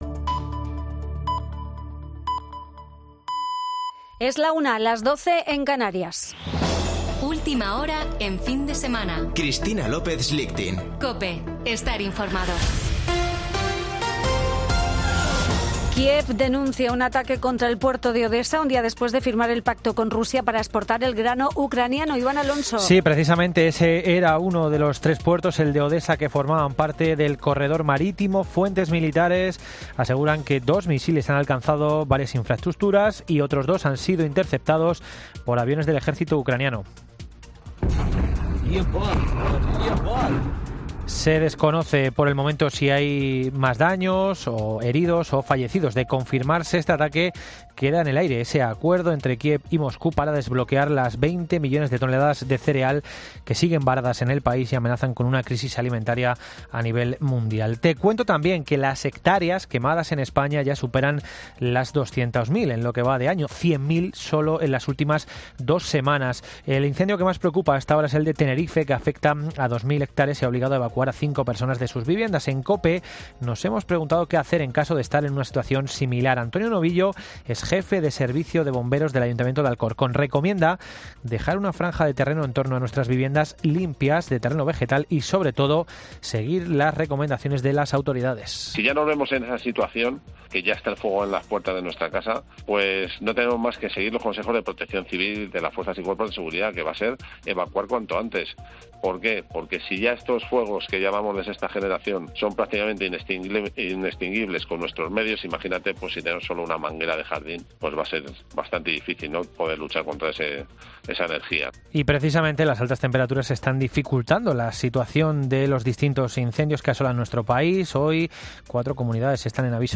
Boletín de noticias de COPE del 23 de julio de 2022 a la 13.00 horas